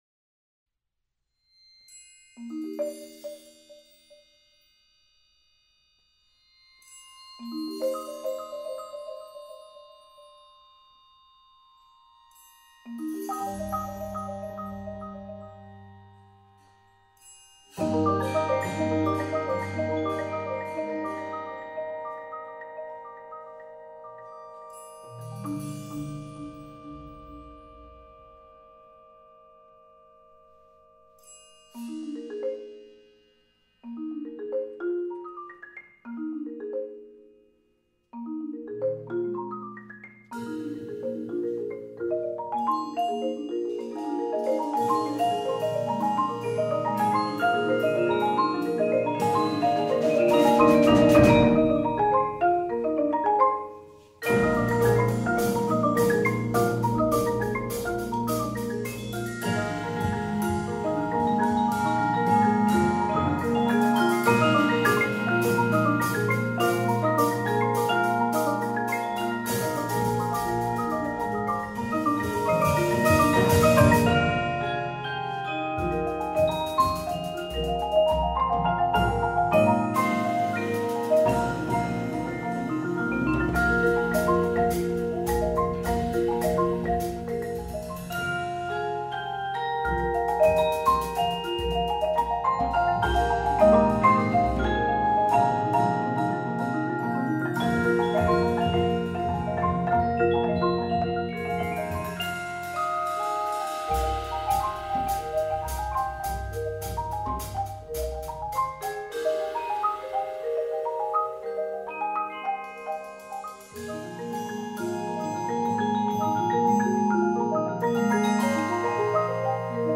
Genre: Percussion Ensemble
# of Players: 13
work for large percussion ensemble
Xylophone/Crotales [2 octaves]
Vibraphone 1
Chimes (Wind Chimes, Sizzle Cymbal)
Marimba 1 [4-octave]
Timpani [4 drums]